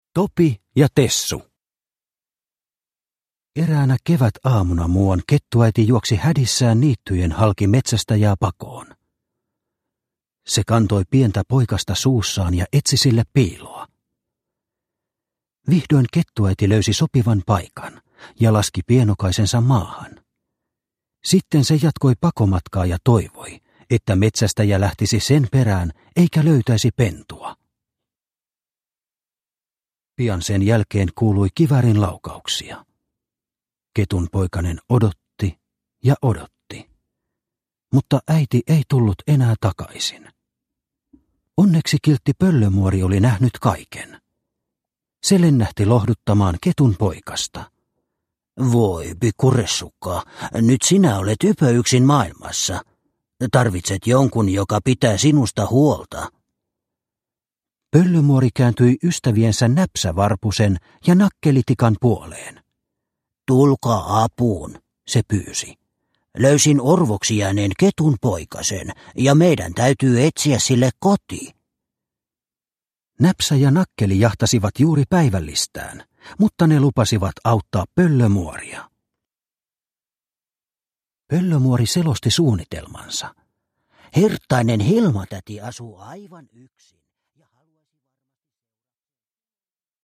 Topi ja Tessu – Ljudbok – Laddas ner